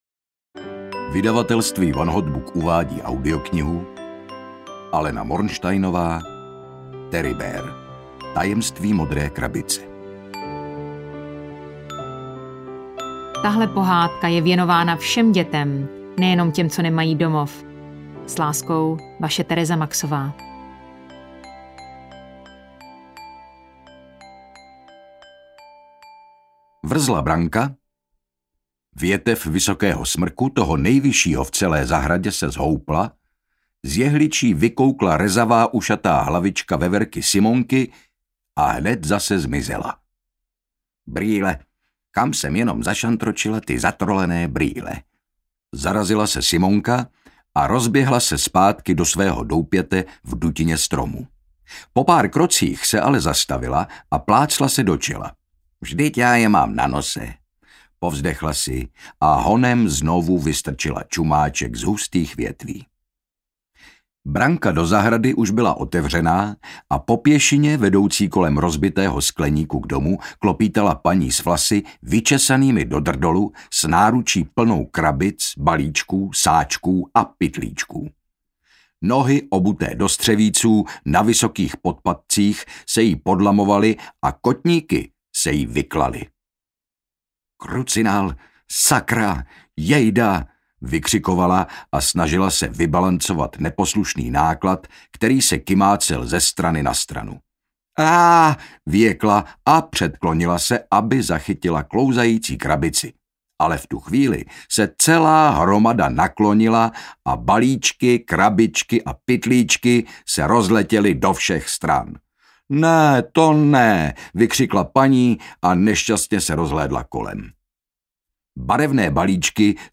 Ukázka z knihy
• InterpretHynek Čermák, Tereza Maxová
teribear-tajemstvi-modre-krabice-audiokniha